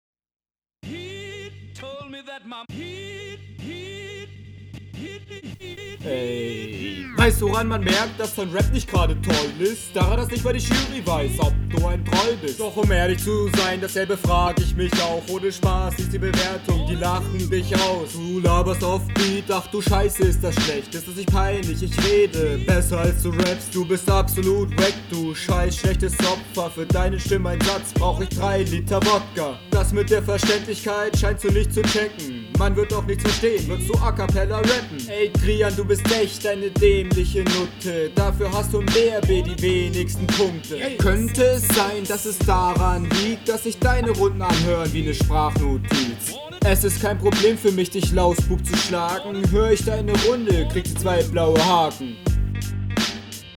Man versteht dich gut .